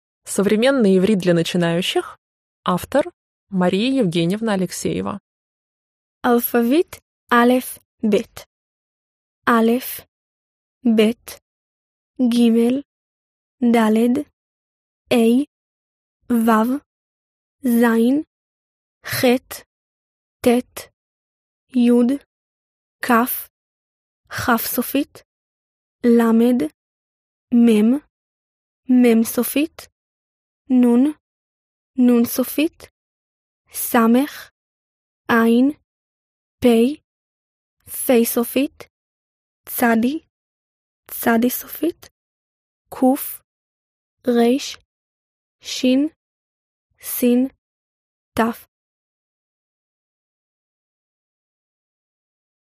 Аудиокнига Современный иврит для начинающих | Библиотека аудиокниг